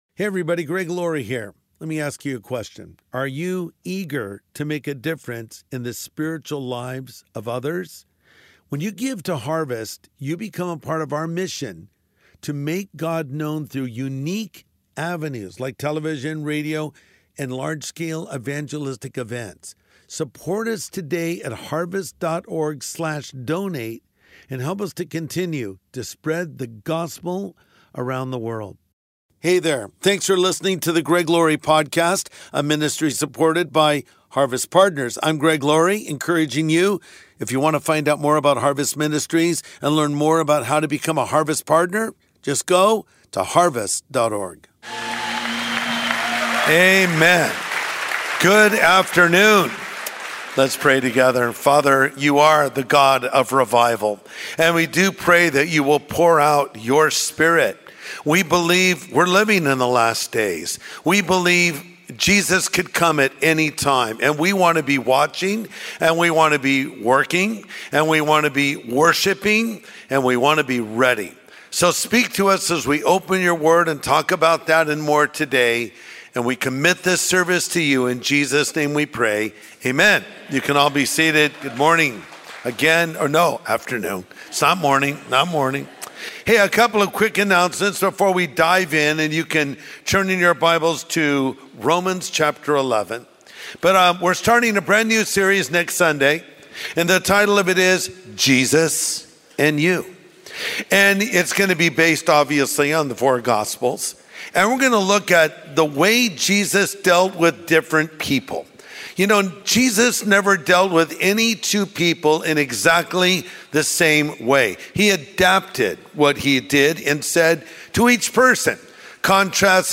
Bible prophecy expert, Joel Rosenberg, joins Pastor Greg Laurie. As they discuss the Israel-Iran conflict, discover why Bible prophecy matters and learn about Israel's significance.